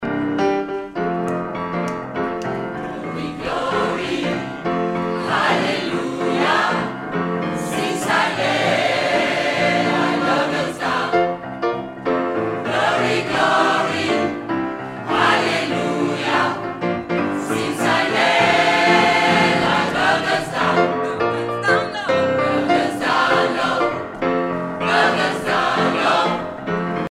Edition discographique Live